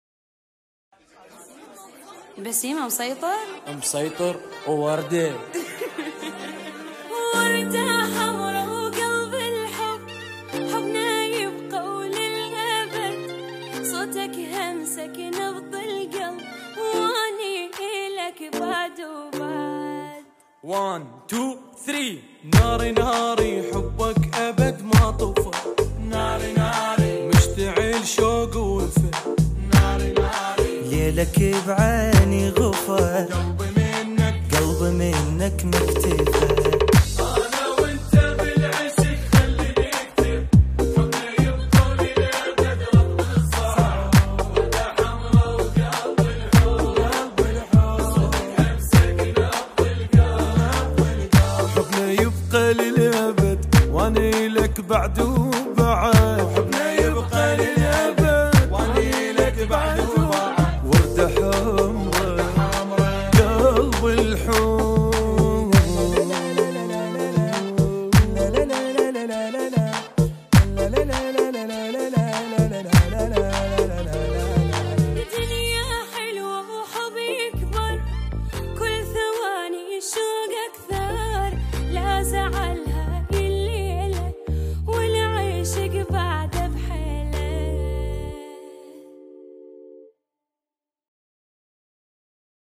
آهنگ عربی شاد و معروف